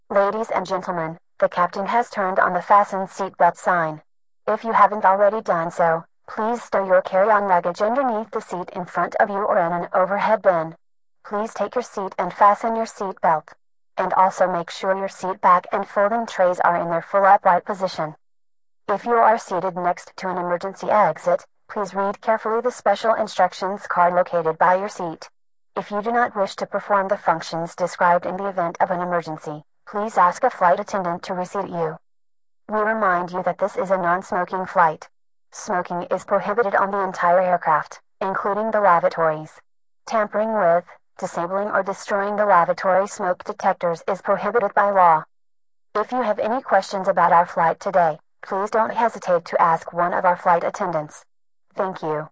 fa_boarding.wav